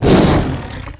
hellhound_attack.wav